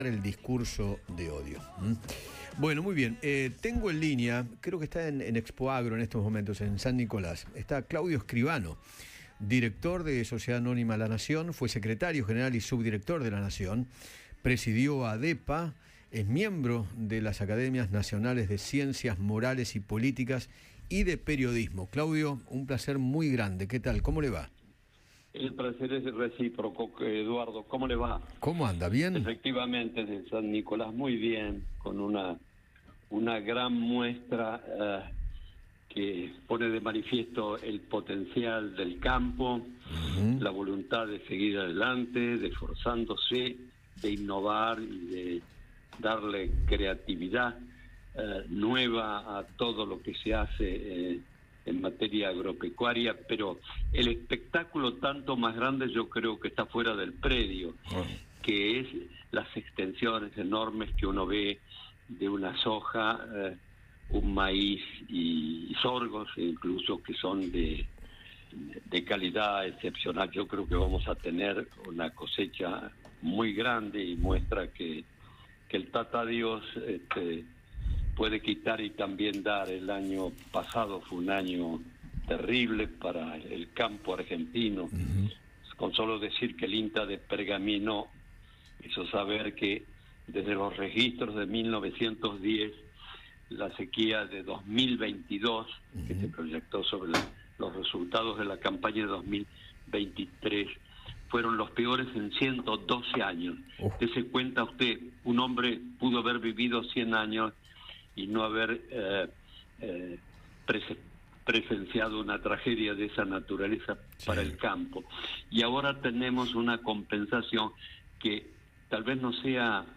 Desde Expoagro 2024, el periodista Claudio Escribano conversó con Eduardo Feinmann sobre la gran voluntad del campo y analizó la personalidad del presidente Javier Milei.